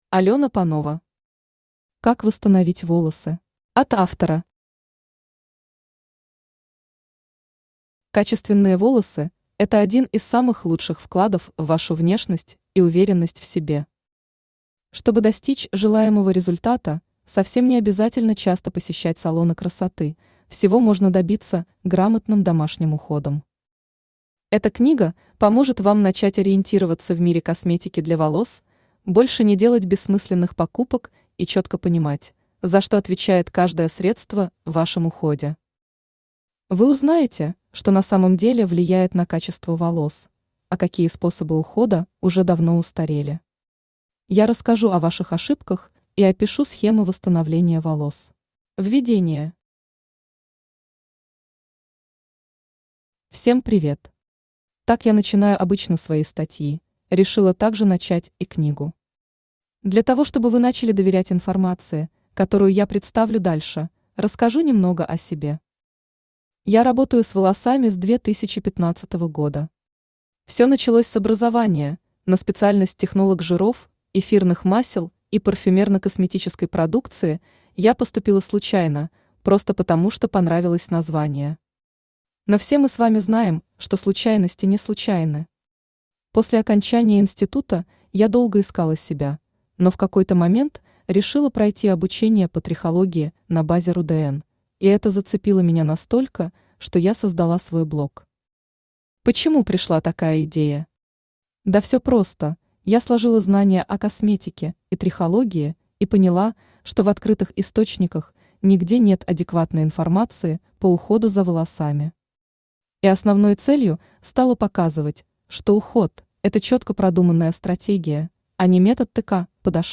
Аудиокнига Как восстановить волосы | Библиотека аудиокниг
Aудиокнига Как восстановить волосы Автор Алена Панова Читает аудиокнигу Авточтец ЛитРес.